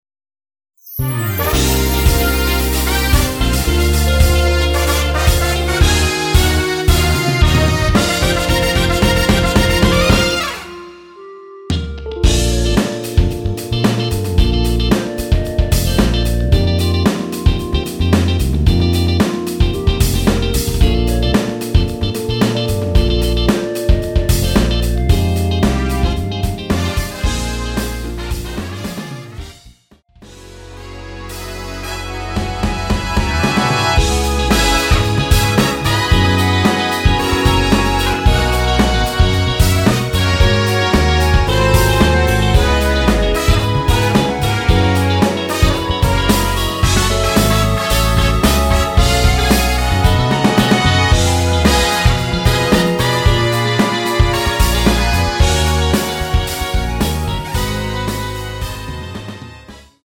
원키에서(+2)올린 멜로디 포함된 MR입니다.
Bb
멜로디 MR이라고 합니다.
앞부분30초, 뒷부분30초씩 편집해서 올려 드리고 있습니다.
중간에 음이 끈어지고 다시 나오는 이유는